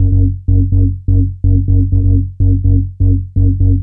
cch_bass_repeater_125_F#m.wav